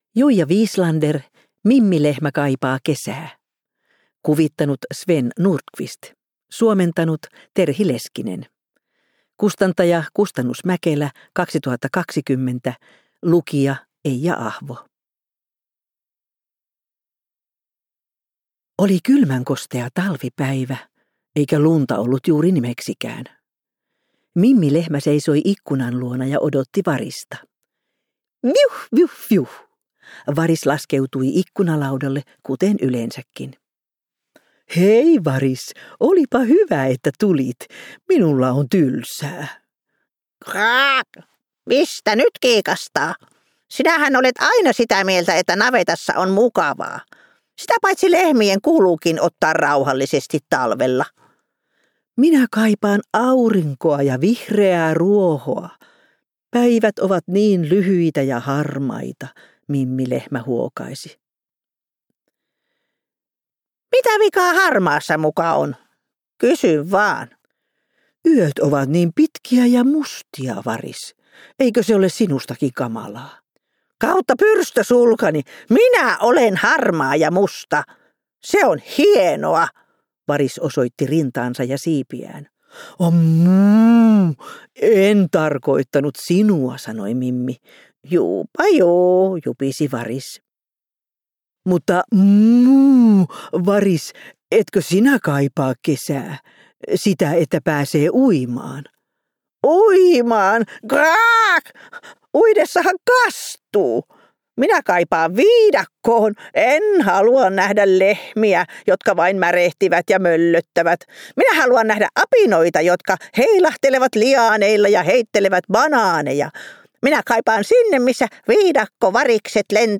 Kuunneltavissa myös äänikirjana useissa eri äänikirjapalveluissa, lukijana Eija Ahvo.